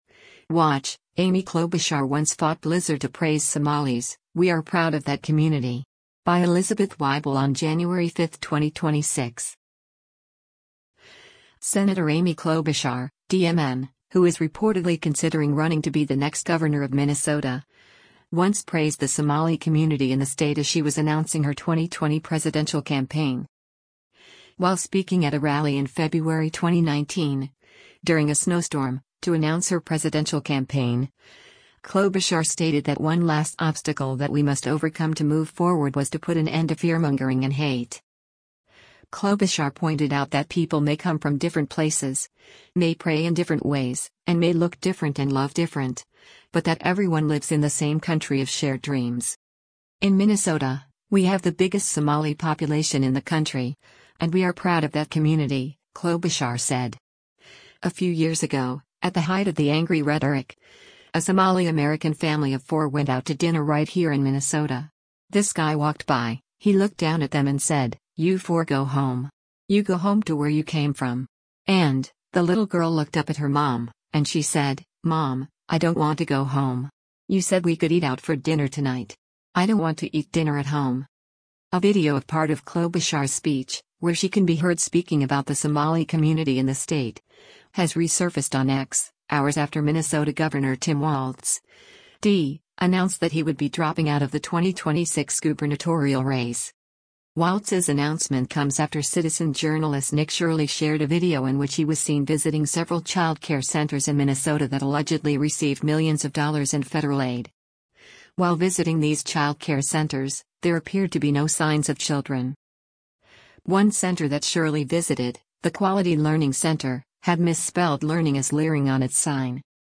While speaking at a rally in February 2019, during a snowstorm, to announce her presidential campaign, Klobuchar stated that “one last obstacle that we must overcome to move forward” was to put an end to “fearmongering” and “hate.”
A video of part of Klobuchar’s speech, where she can be heard speaking about the Somali community in the state, has resurfaced on X, hours after Minnesota Gov. Tim Walz (D) announced that he would be dropping out of the 2026 gubernatorial race.